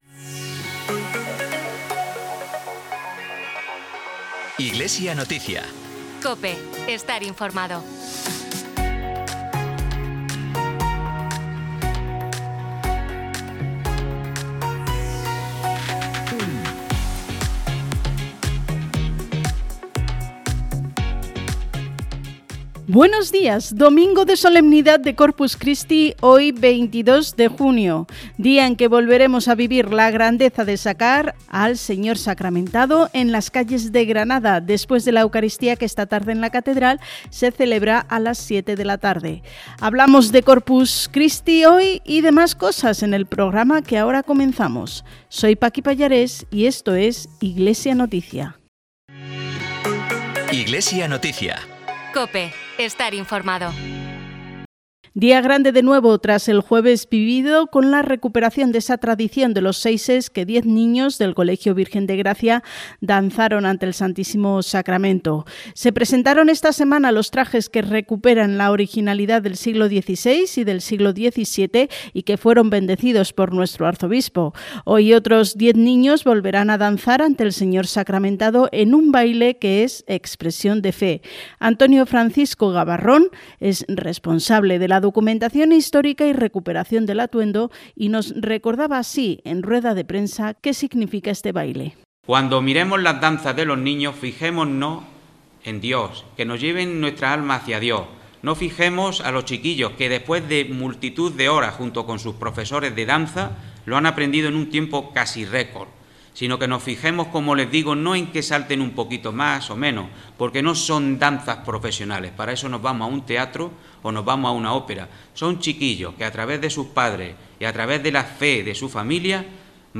Programa emitido en COPE Granada el 22 de junio de 2025.
Disponible el informativo diocesano “Iglesia Noticia”, emitido en COPE Granada y COPE Motril, en el día de la Solemnidad del Corpus Christi, el 22 de junio. Además del Corpus Christi y el Mensaje de nuestro arzobispo con motivo de esta fiesta, hablamos de Cáritas Diocesana en ese día de la caridad, de los Seises de la Catedral de Granada con los que se recupera esta tradición y de las Hospitalarias de Granada que han presentado su Memoria 2024.